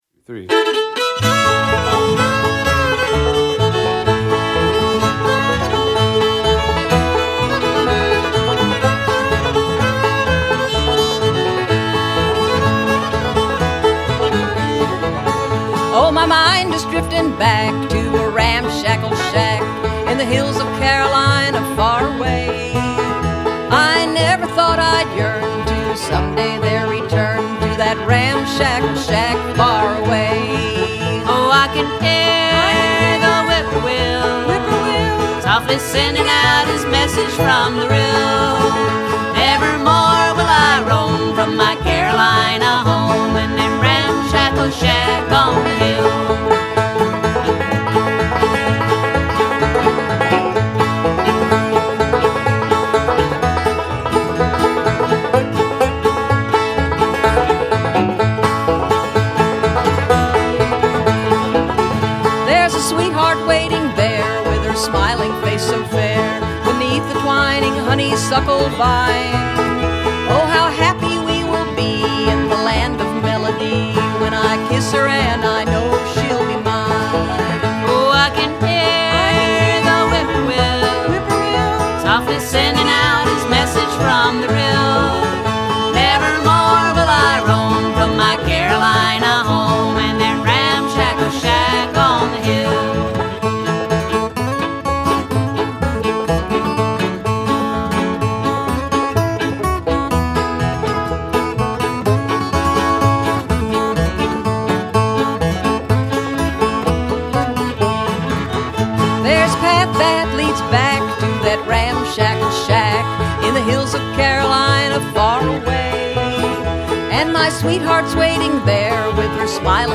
Happy Valley Pals at Merritt Store and Grill
old-time music. When we get together, the Pals mainly play tunes and songs that come from North Carolina, Georgia and Tennessee, states where we have lived or have family roots.